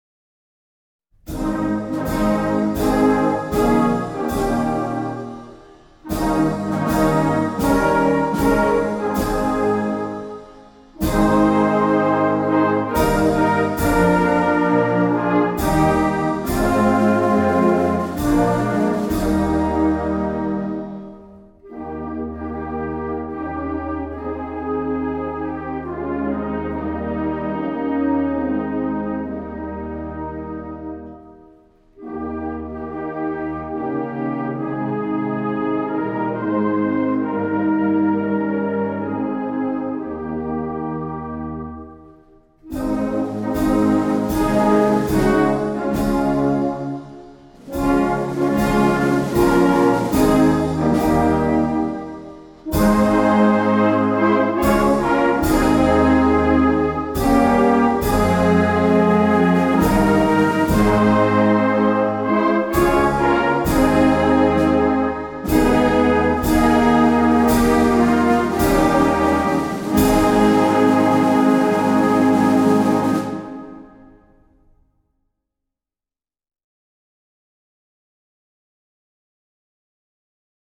A unique presentation of the Swiss National Anthem in the country's four languages. This recording also includes instrumental versions.